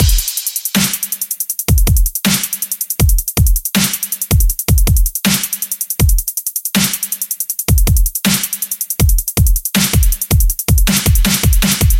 描述：舞蹈和电子音乐|欢快
标签： 合成器